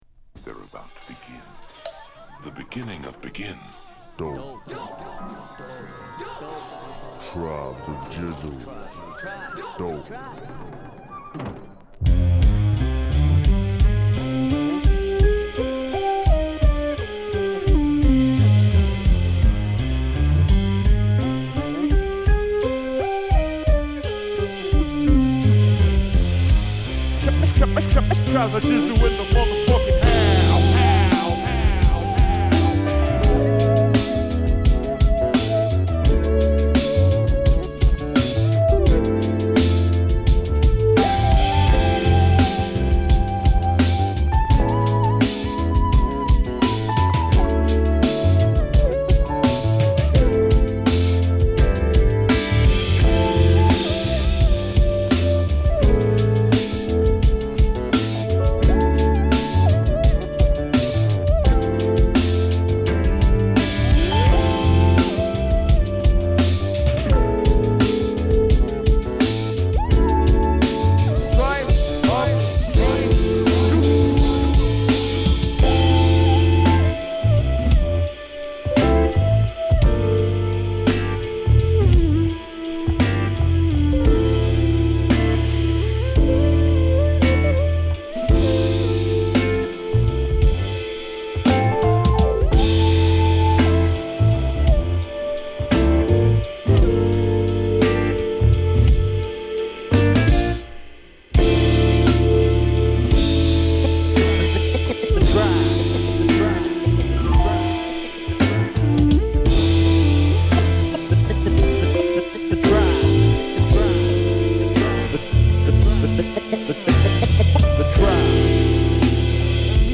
跳ねたビーツにタイトなラップがジャズマナーを強く感じるバンドサウンドと上手く融合してナイスです。
Jazzy Hip Hop , Mellow Groove